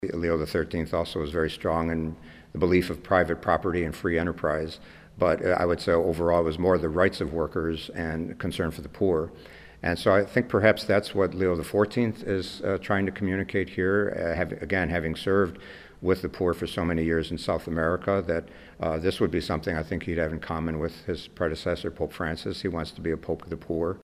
Bishop Thomas John Paprocki speaks in praise of Pope Leo at press conference held early on Friday, May 9, 2025